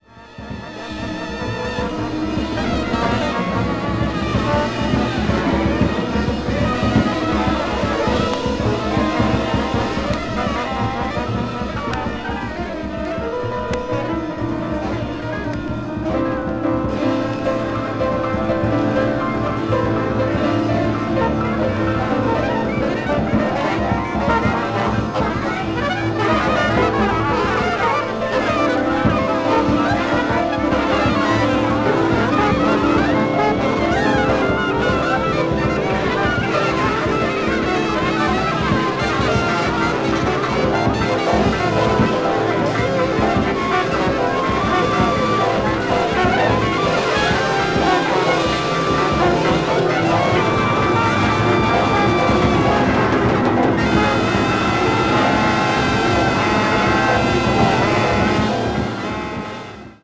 強烈。
avant-jazz   free improvisation   free jazz   jazz orchestra